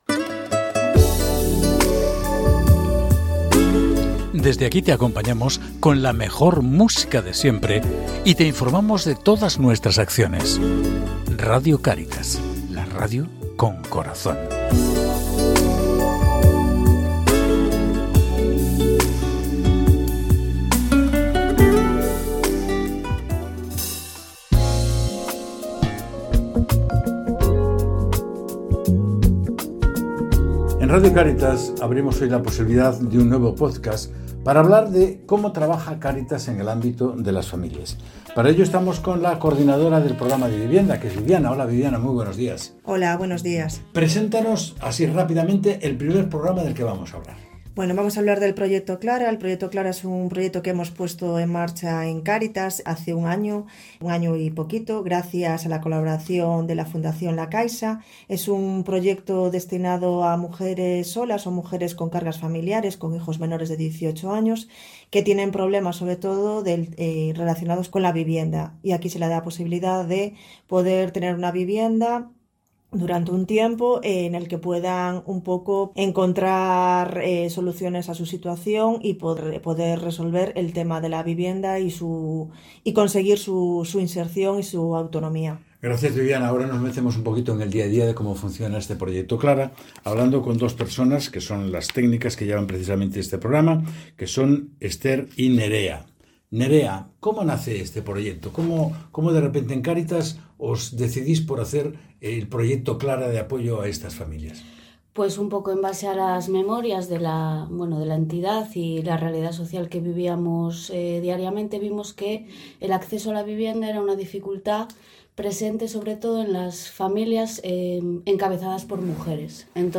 Entrevista-Proyecto-Clara.mp3